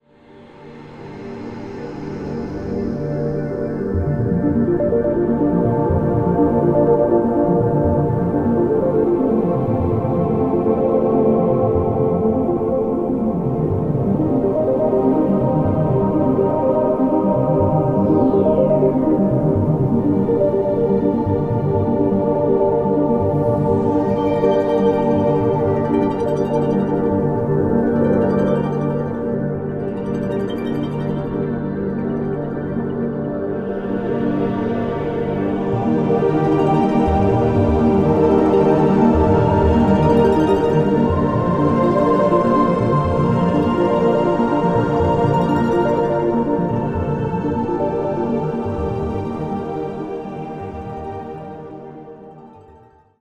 Rebalancing and uplifting.